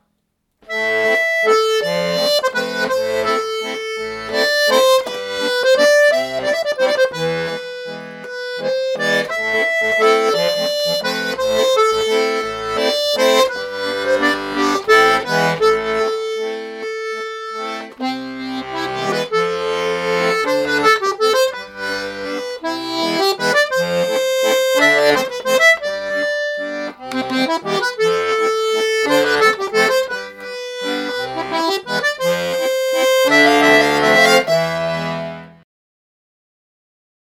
akkd8-HOHNER-Umbau-GC.mp3